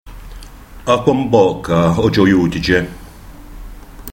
Alors, essayez vous à la prononcer et vérifier avec le fichier son, ce que ça donne de mon coté ( parler du nord, je le rappelle)